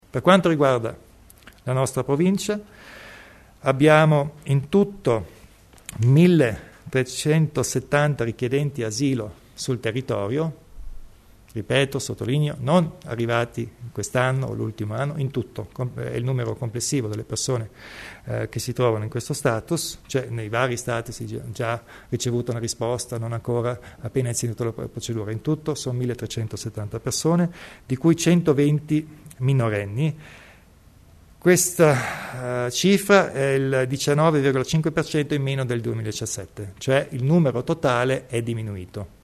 Il Presidente Kompatscher presenta i dati riguardo la presenza di richiedenti asilo in Alto Adige
Lo ha detto il presidente altoatesino Arno Kompatscher durante la conferenza stampa post giunta, facendo il punto della situazione in materia di migranti in seguito al vertice dei ministri europei della scorsa settimana a Innsbruck.